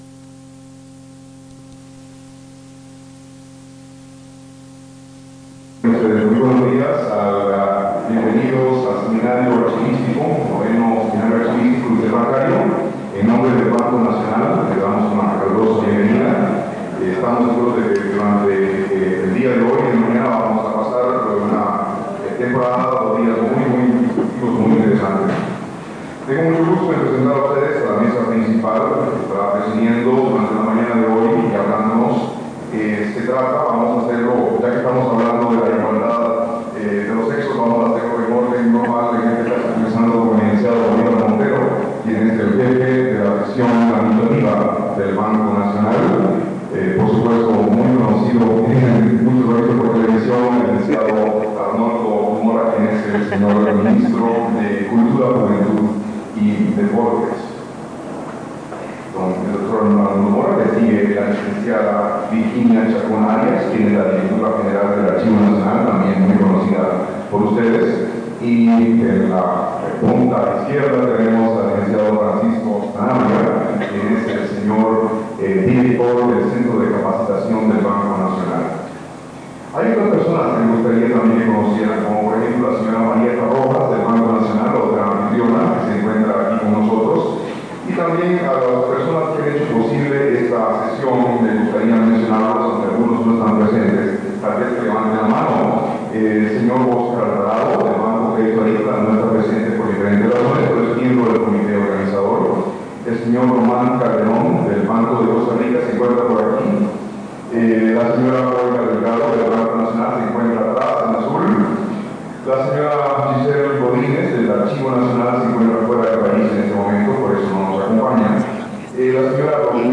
Grabación sobre Seminario Interbancario - Archivo Nacional de Costa Rica
Notas: Casete de audio y digital